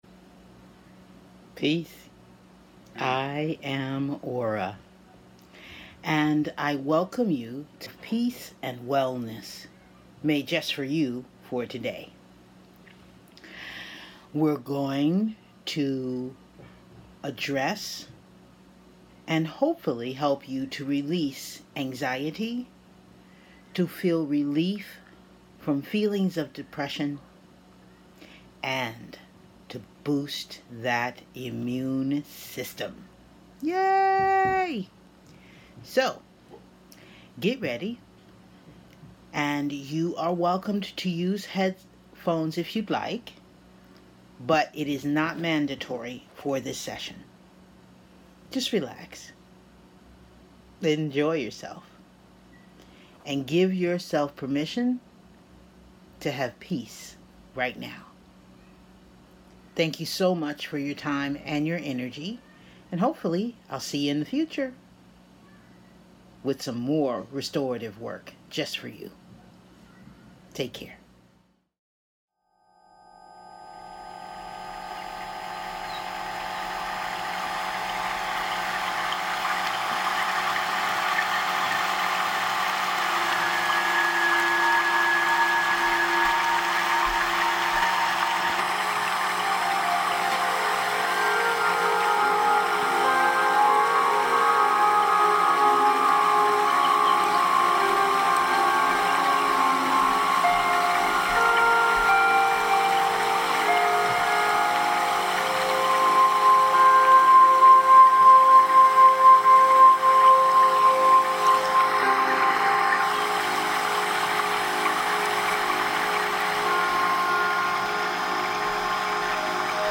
[DAY 4] 5-Min Peace & Wellness Meditation
Before you learn my 5 steps to peaceful conflict resolution in the DAY 4 Activity, take the next few minutes to relax with this sound bath of healing tones to bring peace and wellness to your day!
**The tones you hear are part of the audio and purposefully set in specific sound ranges.